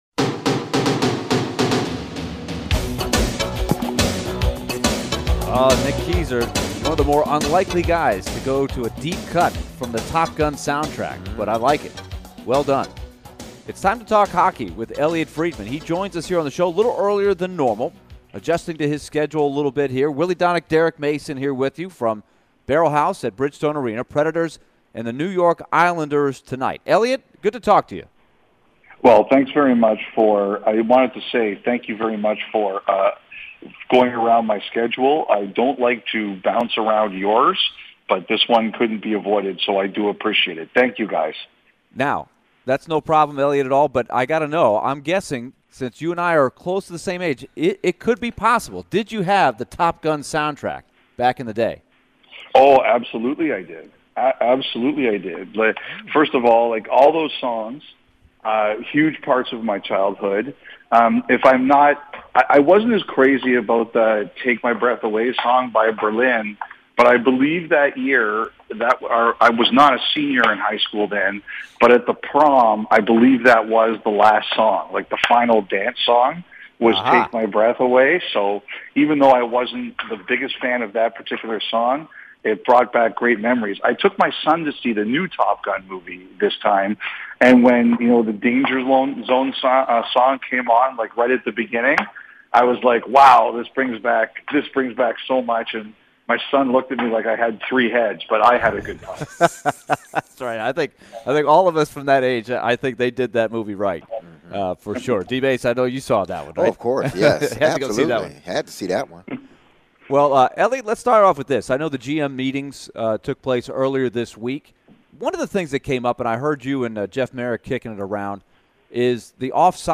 Elliotte Friedman interview (11-17-22)